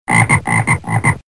صوت الضفدع – 2